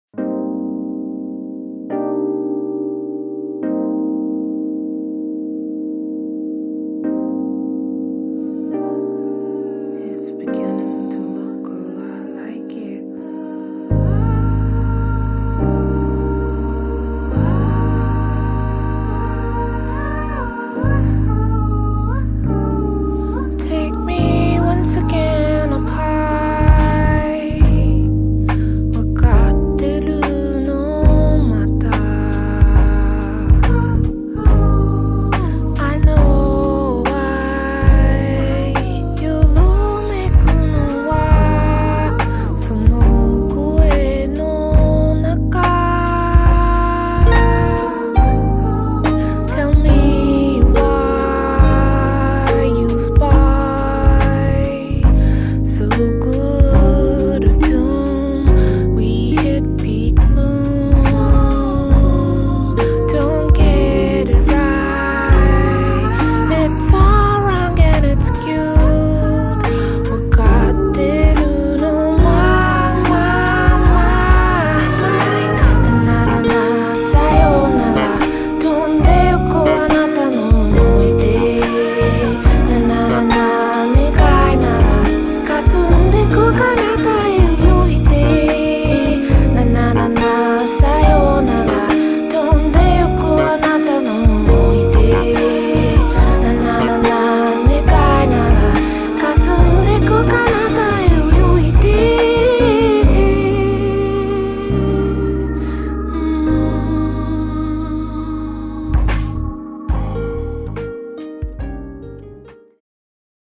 Harp.